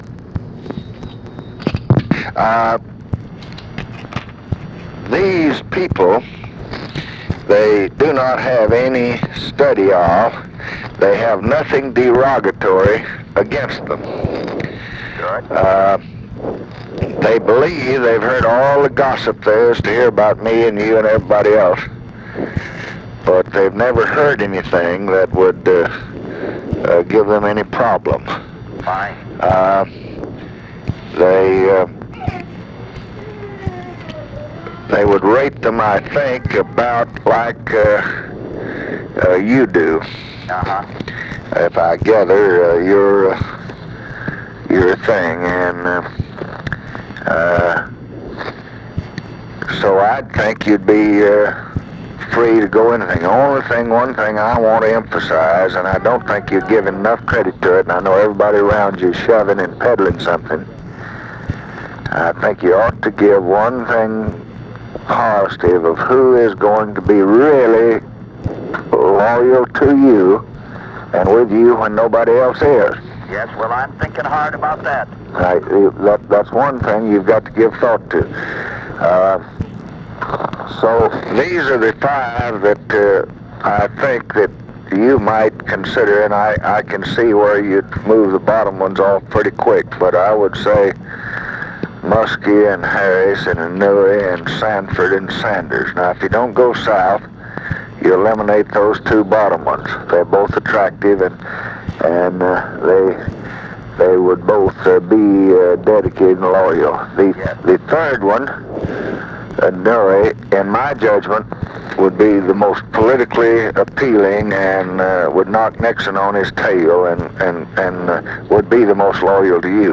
Conversation with HUBERT HUMPHREY, August 29, 1968
Secret White House Tapes